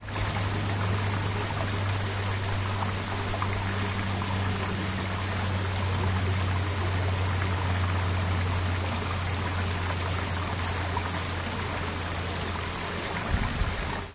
木头里的小溪
描述：森林中的一条涓涓细流。
Tag: 涓涓细流 咿呀学语